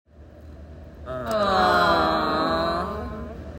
Awww Disappointed Sound Effect Download: Instant Soundboard Button
Reactions Soundboard1,136 views